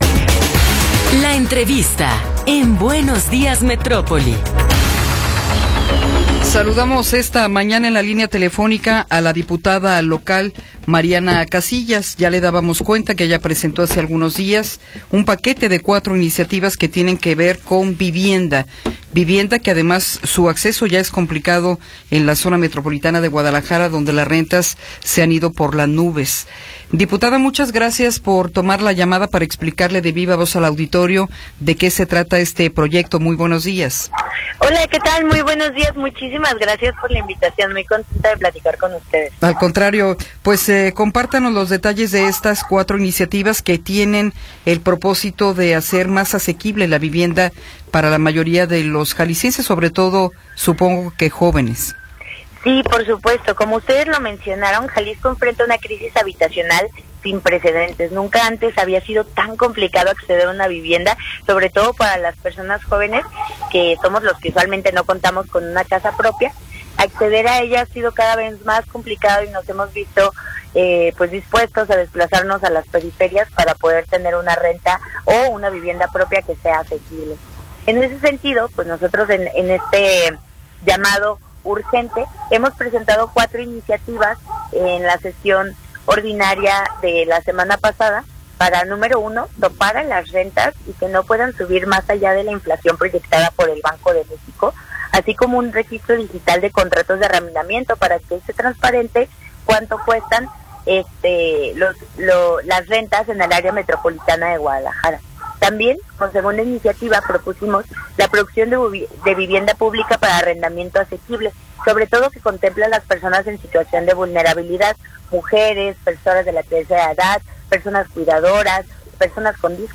Entrevista con Mariana Casillas